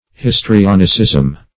Search Result for " histrionicism" : The Collaborative International Dictionary of English v.0.48: Histrionicism \His`tri*on"i*cism\, n. The histrionic art; stageplaying.